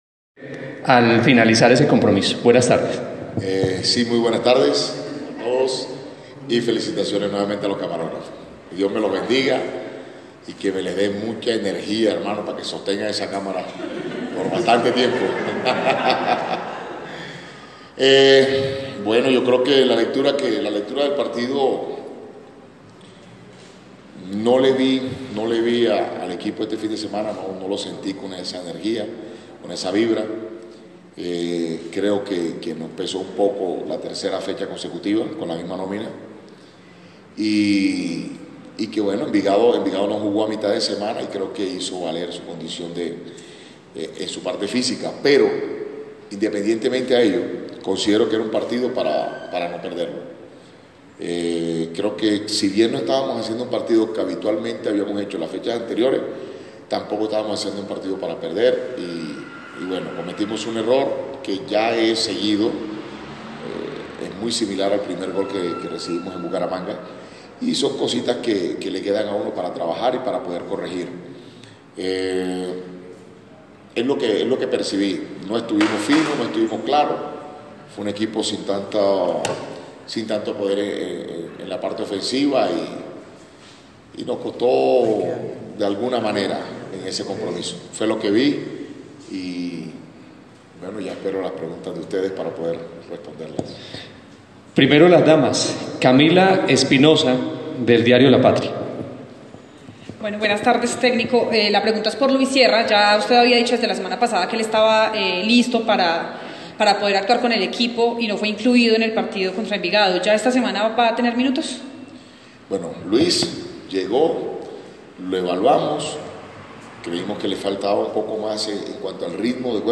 Declaraciones del entrenador en Rueda de Prensa.